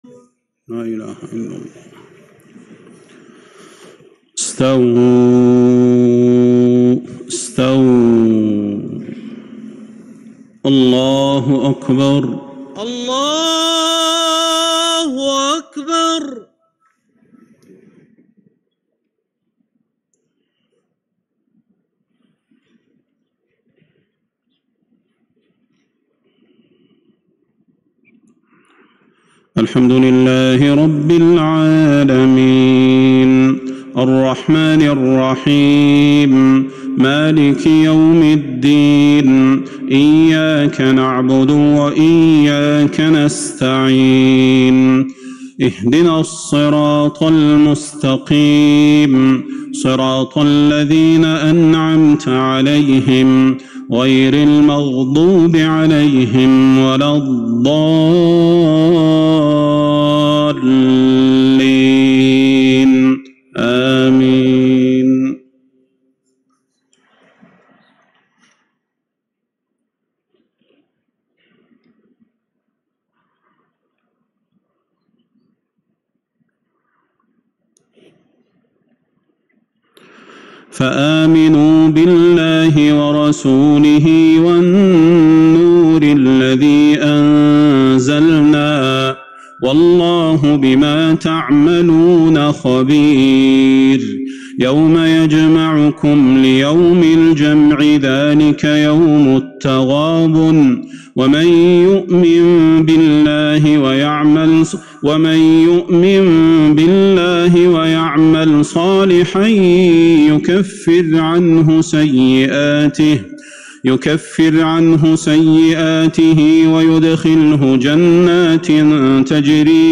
صلاة العشاء من سورة التغابن ١١/٢/١٤٤٢ isha prayer from surah At-Taghabun 28/9/2020 > 1442 🕌 > الفروض - تلاوات الحرمين